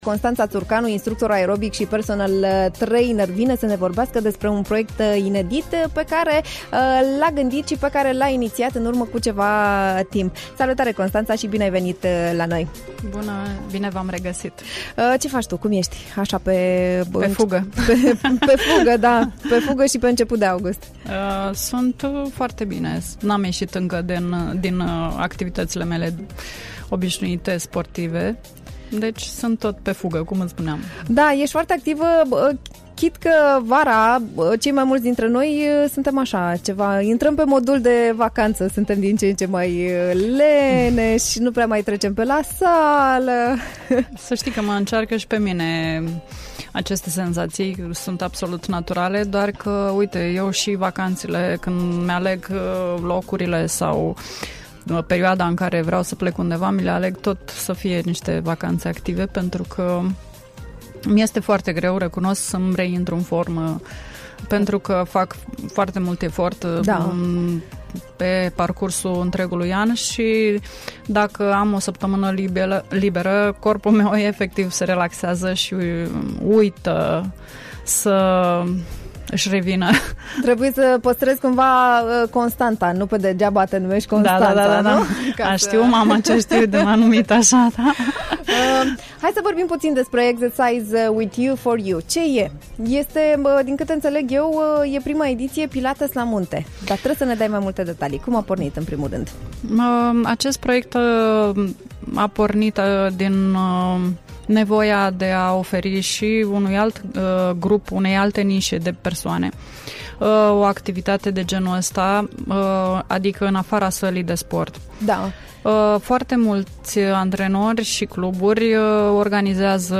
In Be the HIT, am stat de vorbă cu instructorul de aerobic și personal trainer